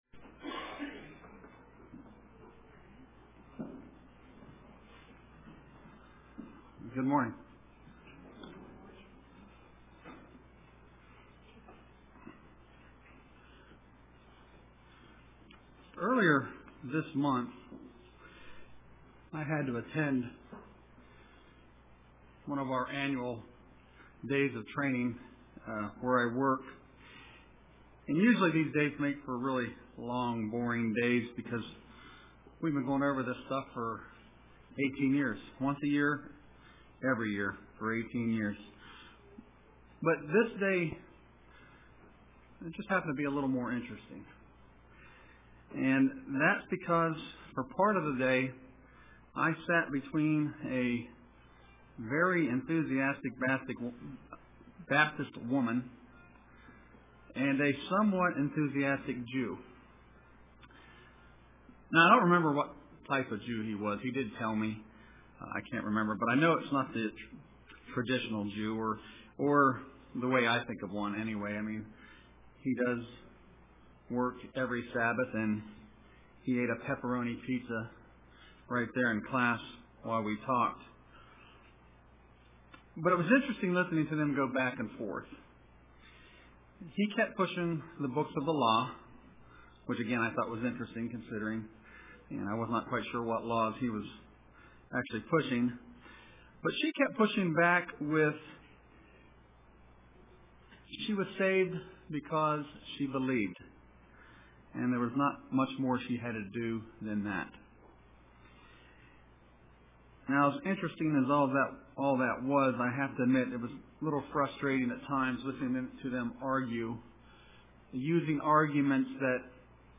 Print Moving Forward UCG Sermon